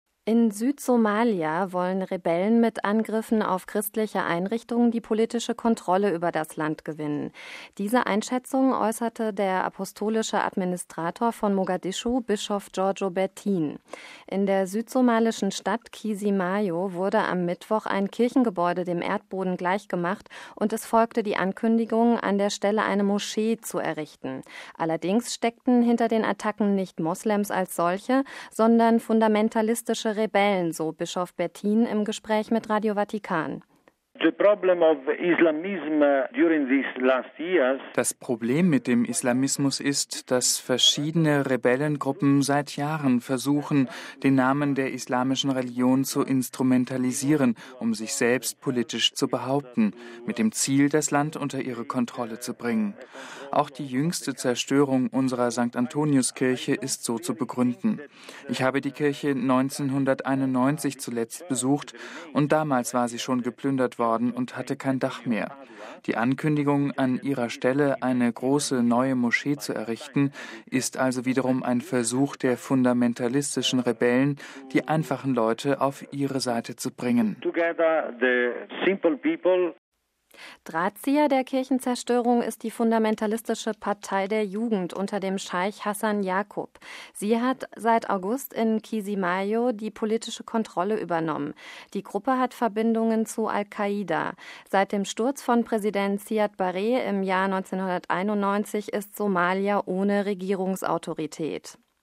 In der südsomalischen Stadt Chisimaio wurde am Mittwoch ein Kirchengebäude dem Erdboden gleichgemacht, und es folgte die Ankündigung, an der Stelle eine Moschee zu errichten. Allerdings steckten hinter den Attacken nicht Moslems als solche, sondern fundamentalistische Rebellen, so Bischof Bertin im Gespräch mit Radio Vatikan: